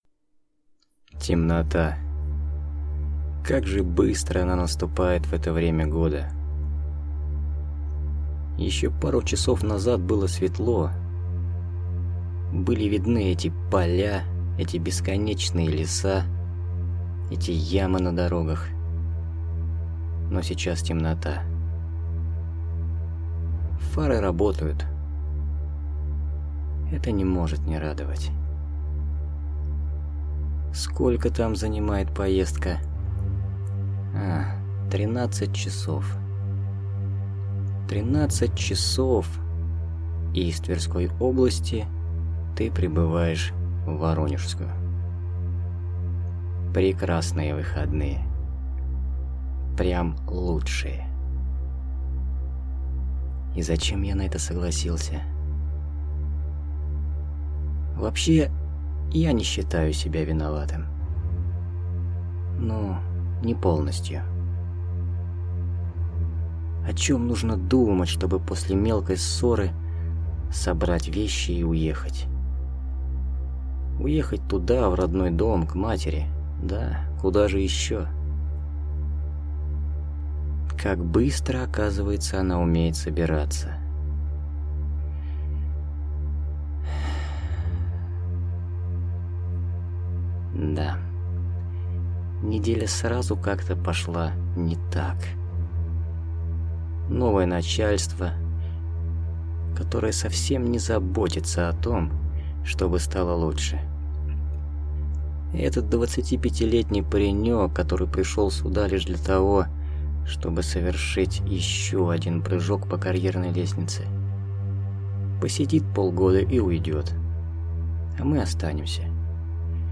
Аудиокнига Сказки старого леса. Ночь. Часть 1 | Библиотека аудиокниг